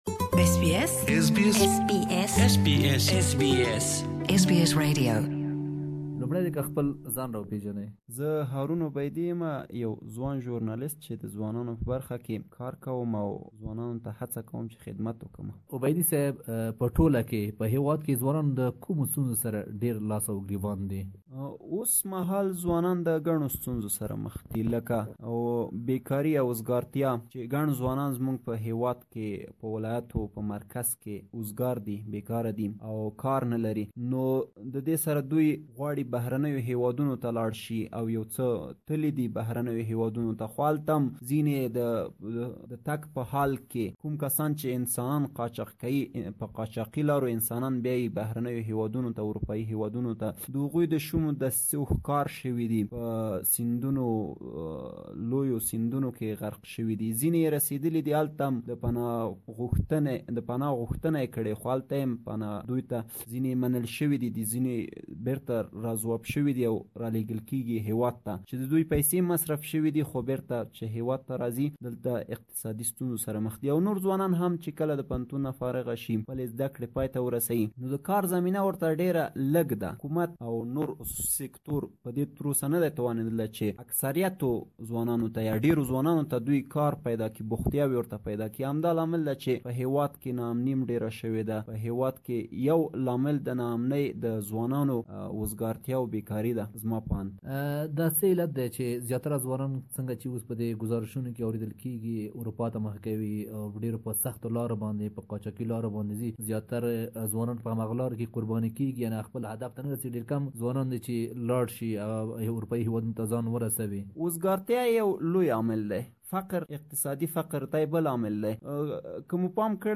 In Afghanistan youth are facing different kinds of challenges and many believe that ignoring the problems of youths leads to insecurity and instability. We have looked at youth issues through the eyes of young Afghan and you can listen to his full interview here.